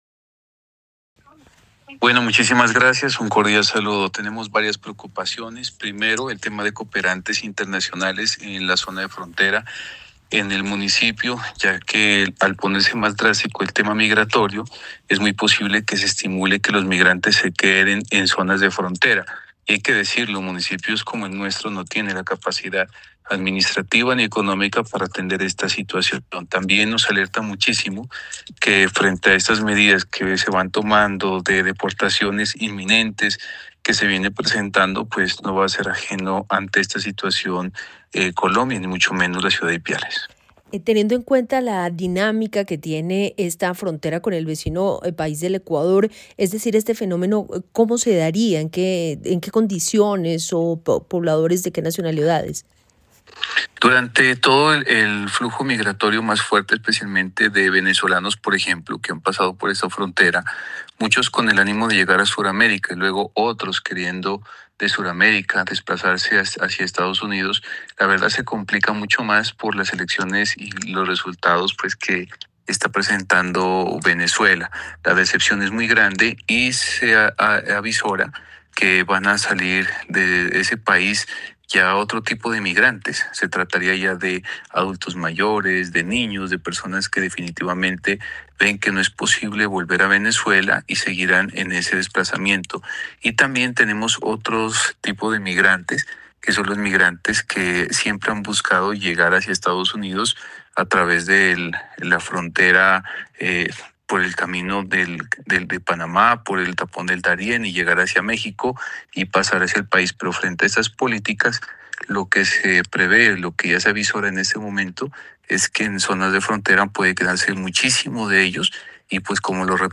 Amilcar Pantoja, alcalde de Ipiales